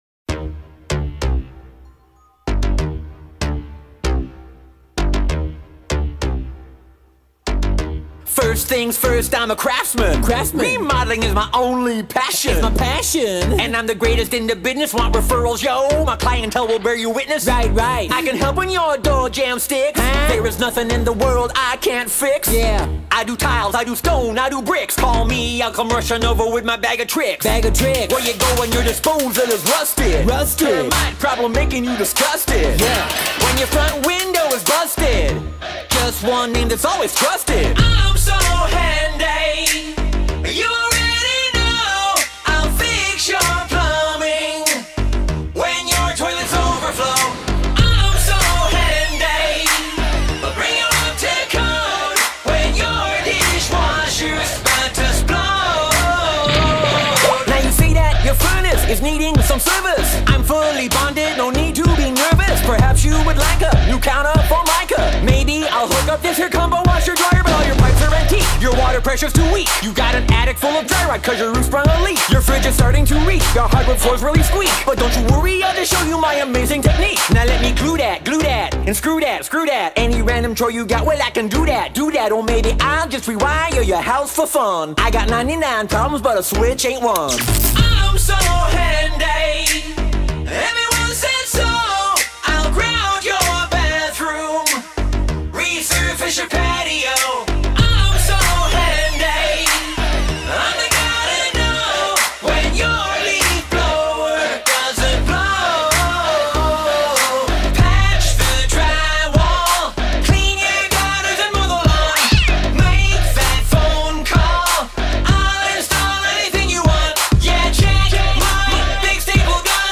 BPM96
Audio QualityPerfect (High Quality)
Comments(FULL SONG)